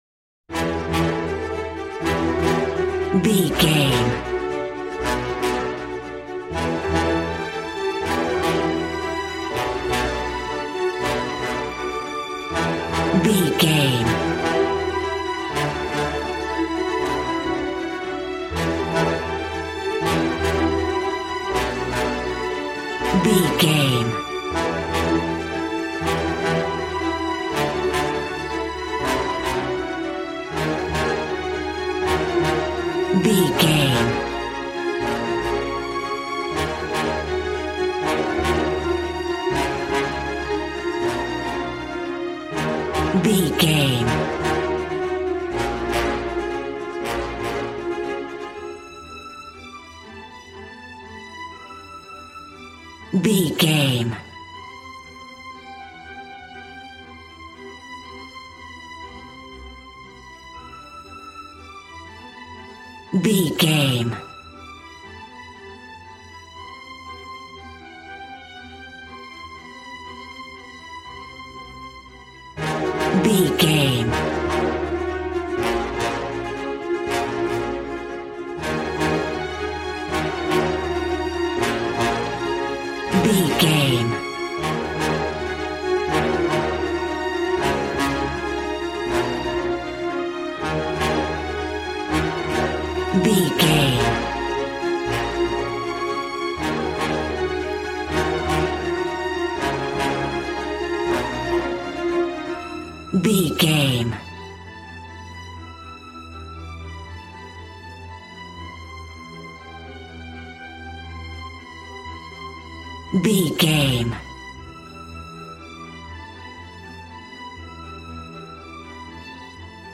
Regal and romantic, a classy piece of classical music.
Aeolian/Minor
A♭
regal
cello
violin
strings